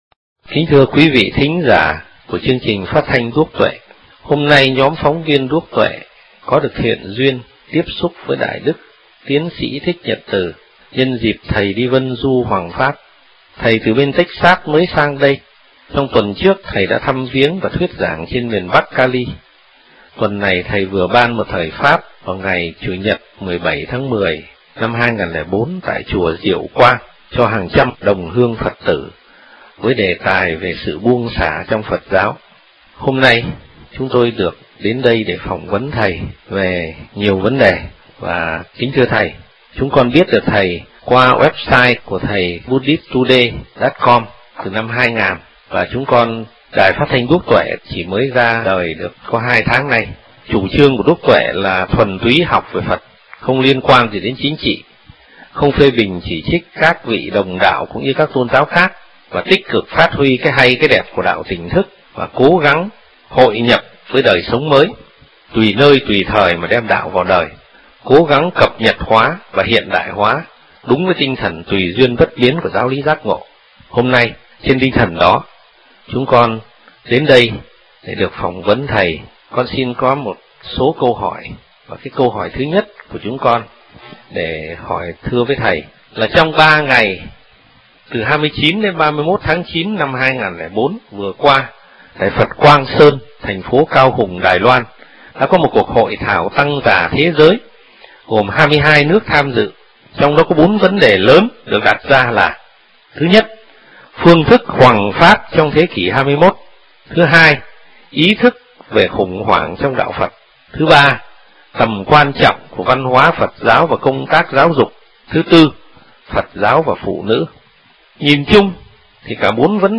Trả lời phỏng vấn đài Đuốc Tuệ